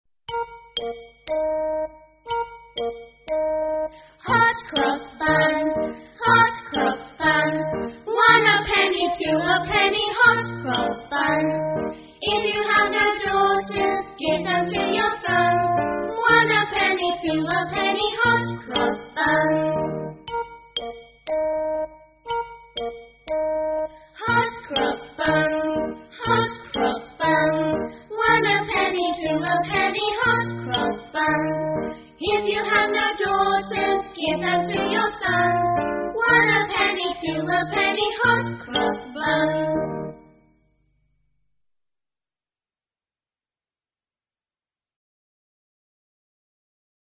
鹅妈妈童谣 51 Hot Cross Buns 听力文件下载—在线英语听力室